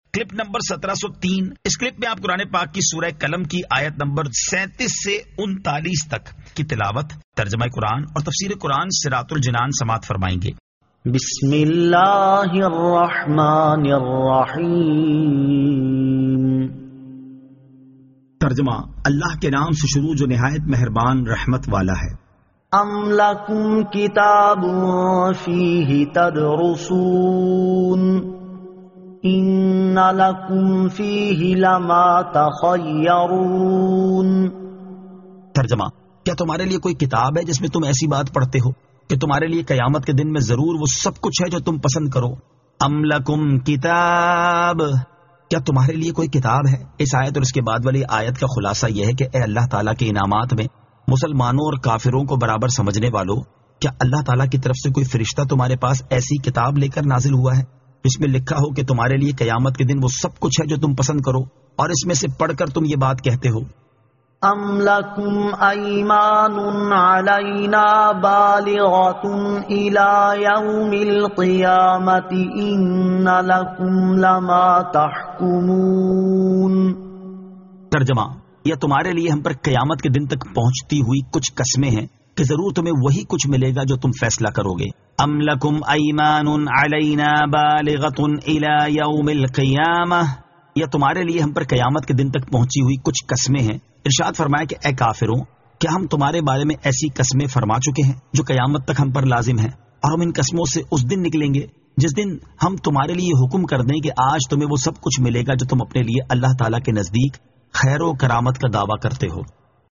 Surah Al-Qalam 37 To 39 Tilawat , Tarjama , Tafseer